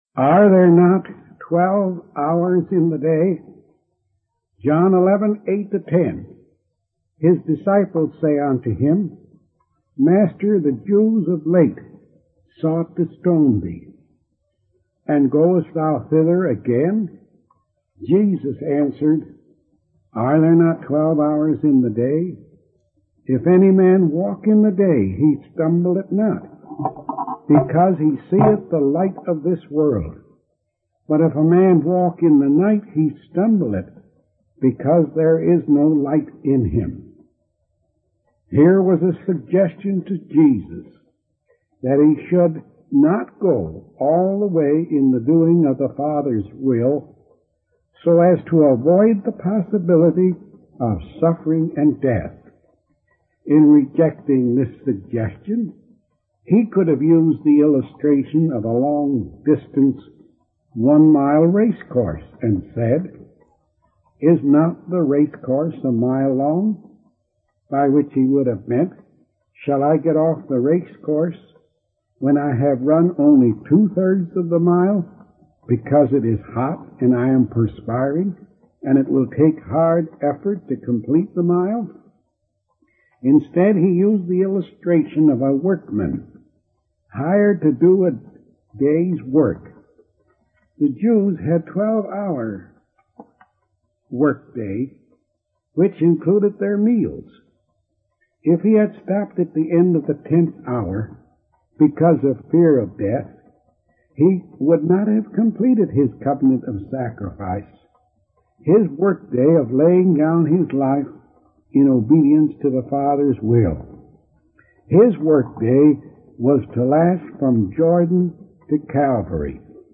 From Type: "Discourse"